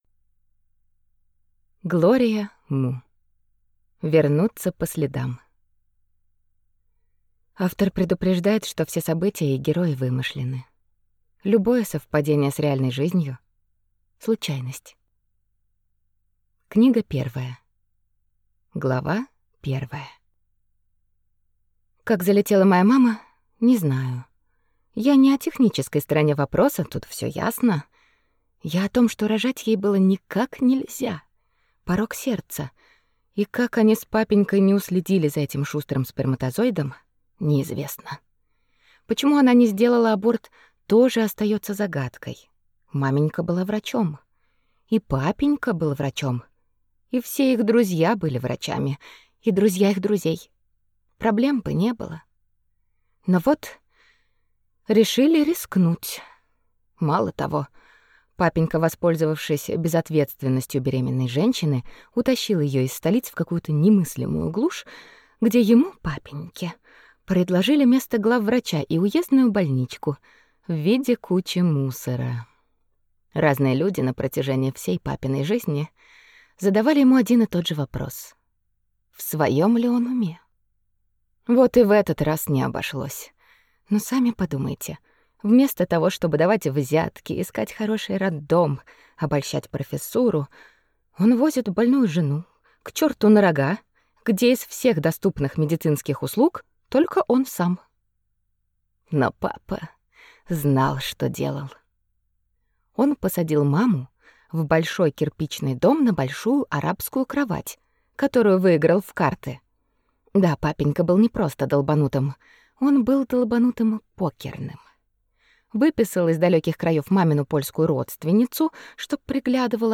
Аудиокнига Вернуться по следам | Библиотека аудиокниг